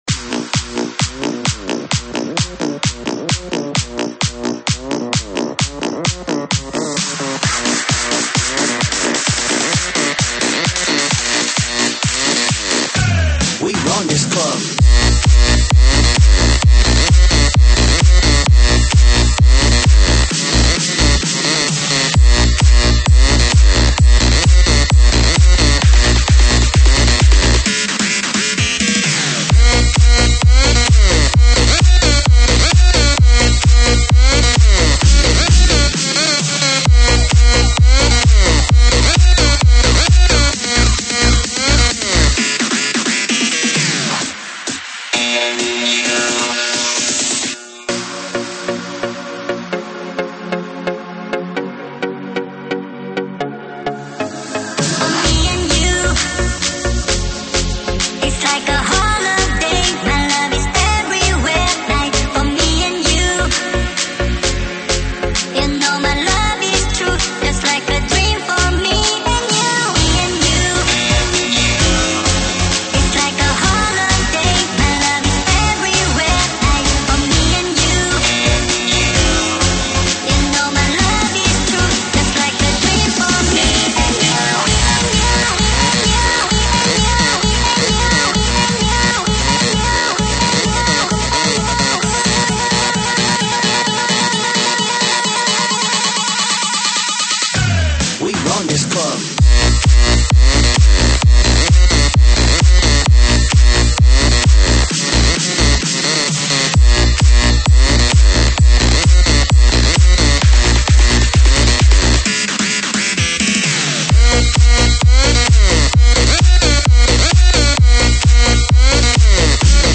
BPM速度有2个版本。